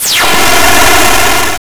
GameOver.wav